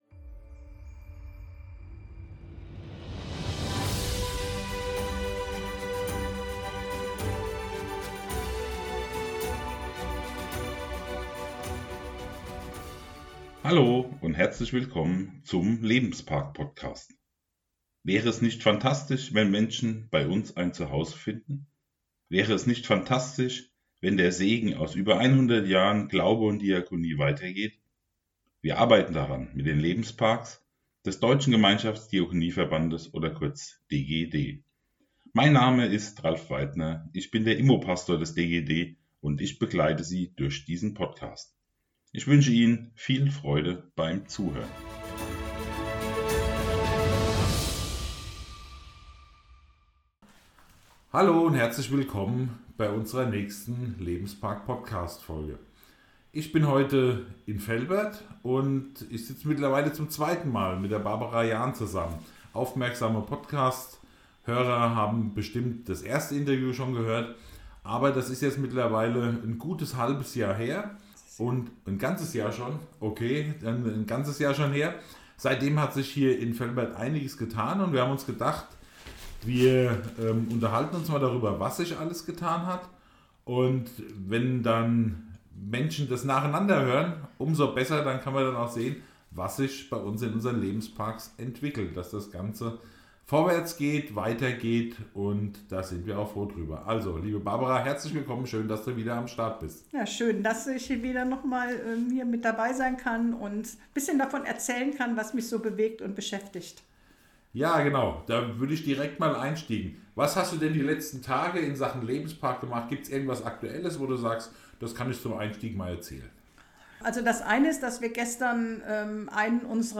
In jeder Episode sprechen wir mit Menschen aus dem Umfeld der Diakonissenhäuser und der Lebensparks.